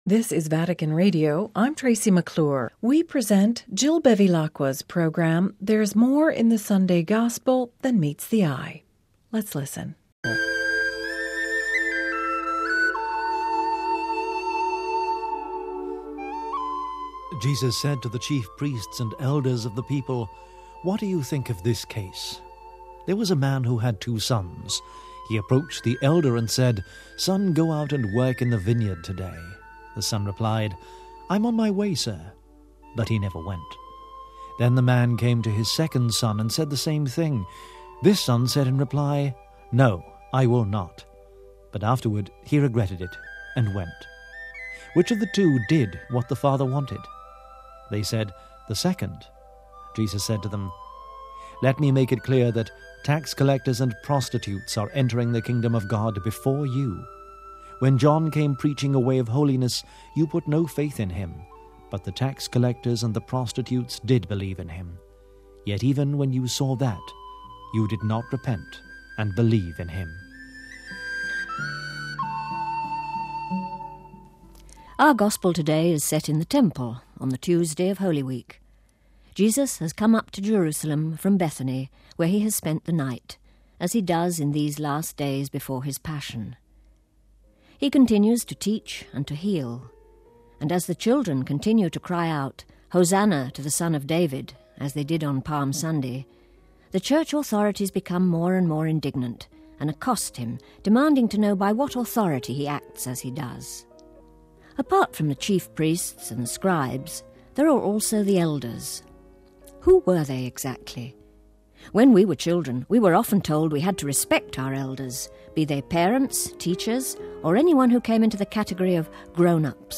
readings and reflections